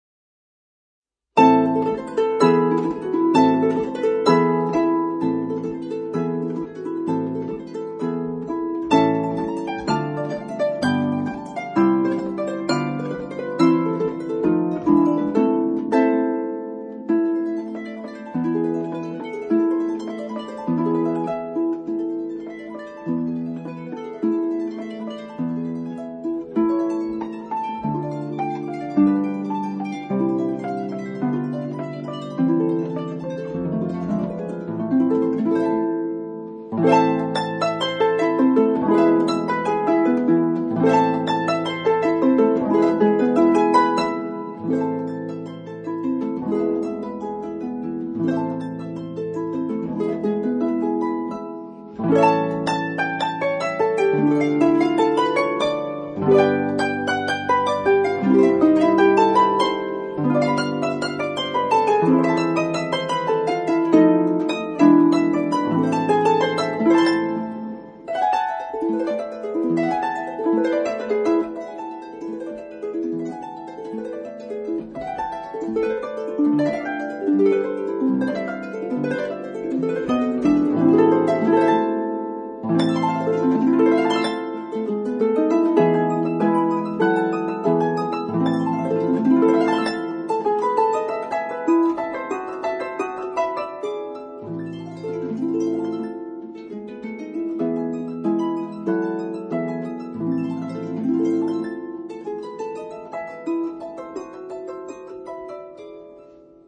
可以聽聽豎琴如何處理這段名曲。
卻很像是現代精準的機關槍，敢連發，還能全命中。